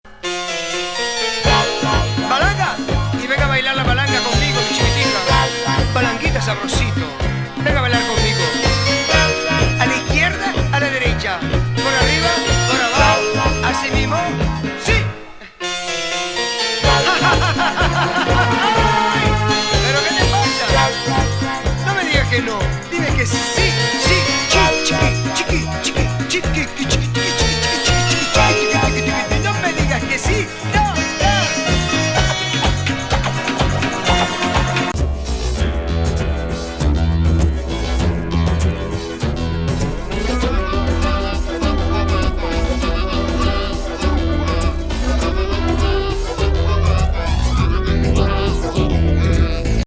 フレンチ・ズンドコ・ファンク